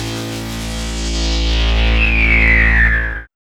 2505L SYNSWP.wav